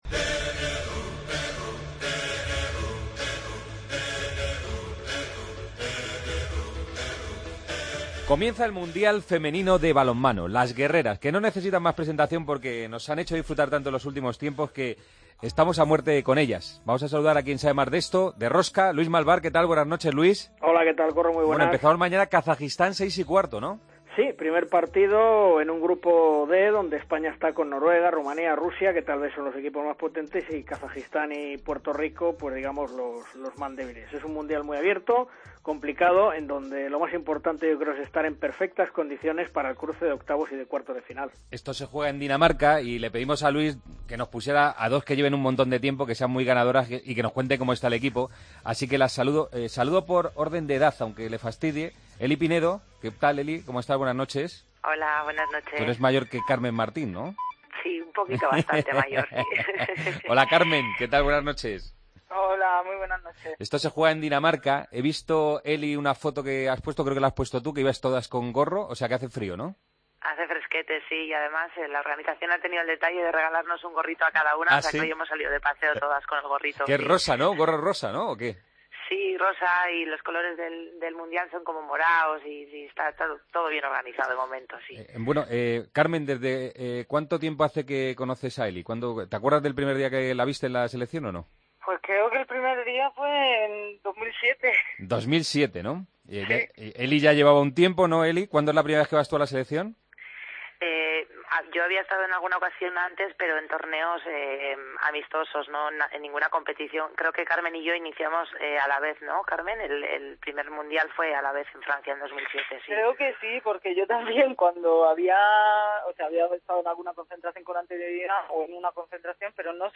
Las dos jugadores de la selección hablan del Mundial de Balonmano, en la previa del comienzo del campeonato: